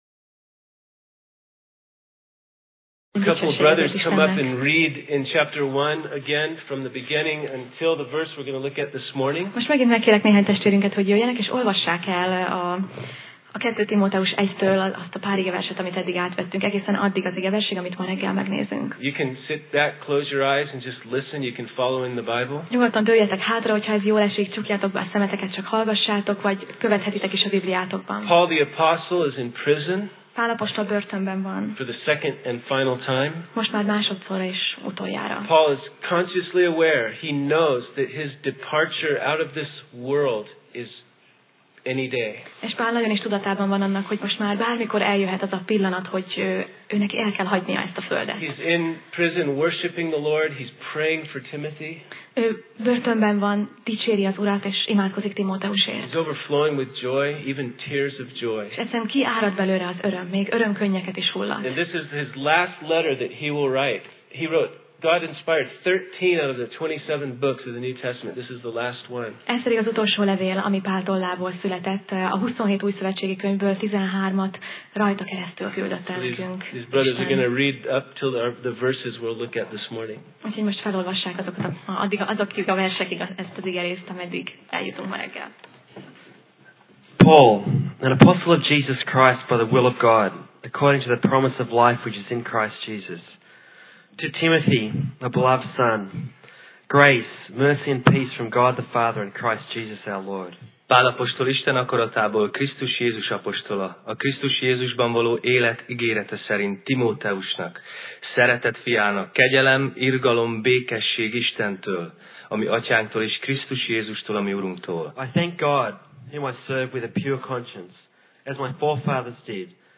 2Timóteus Passage: 2Timóteus (2Timothy) 1:10 Alkalom: Vasárnap Reggel